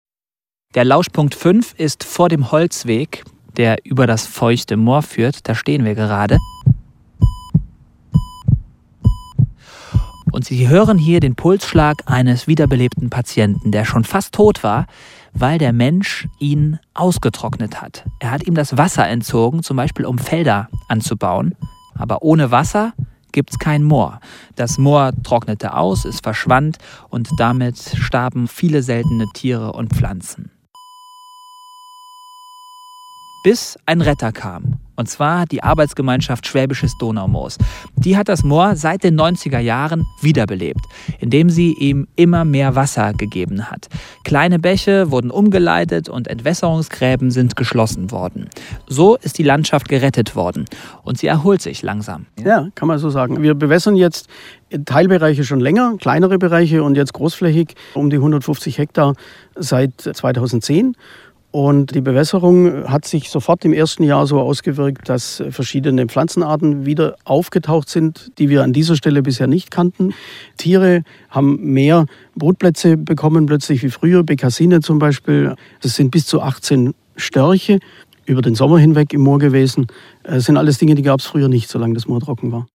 Ein Moor fürs Ohr: Schwarzer Torf und tiefe Tümpel, hören Sie den Pulsschlag eines wiederbewässerten Moors!.
Ein Biologe erklärt faszinierende Naturphänomene. Außerdem gibt’s Interviews mit ehemaligen Torf stechern und wir schauen bei einer Straußenfarm vorbei.